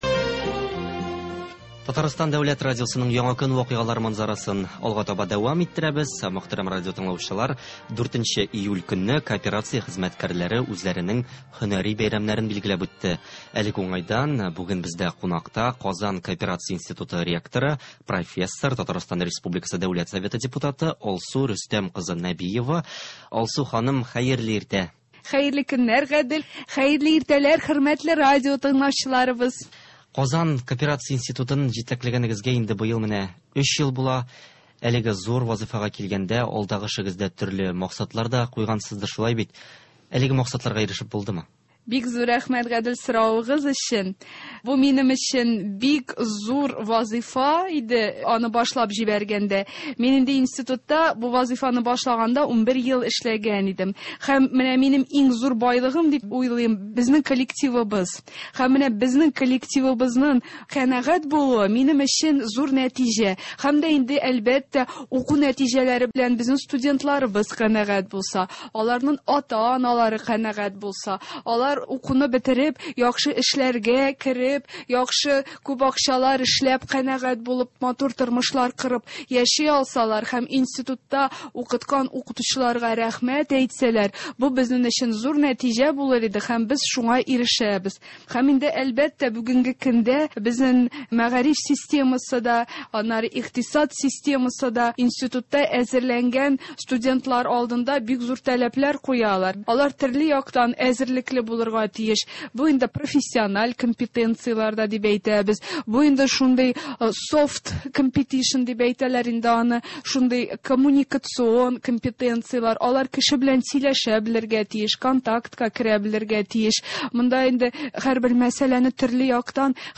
Хөрмәтле радиотыңлаучылар, 4нче июль көнне кооперация хезмәткәрләре үзләренең һөнәри бәйрәмнәрен билгеләп үтте. Әлеге уңайдан бүген бездә кунакта Казан кооперация институты ректоры, Профессор, ТР Дәүләт Советы депутаты Алсу Рөстәм кызы Нәбиева.